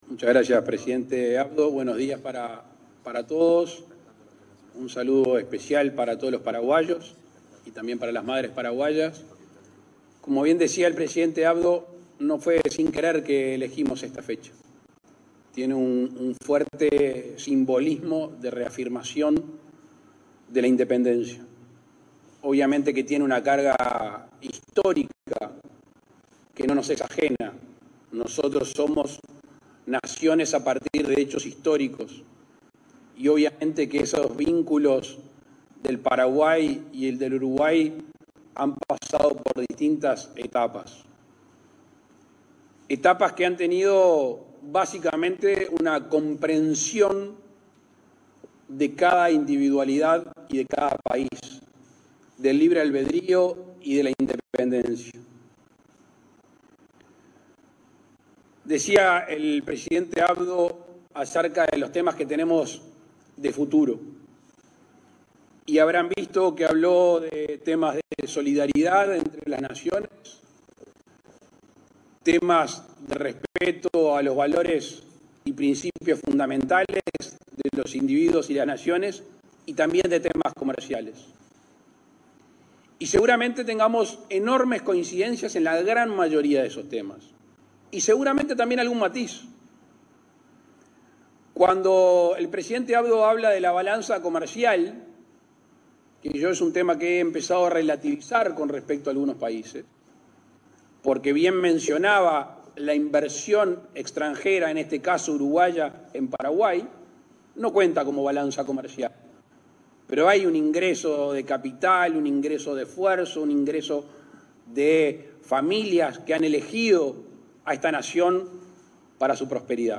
Palabras del presidente de la República, Luis Lacalle Pou, en Paraguay
Tras participar en actividades con el presidente de Paraguay, Mario Abdo Benítez, el mandatario uruguayo, Luis Lacalle Pou, efectuó declaraciones.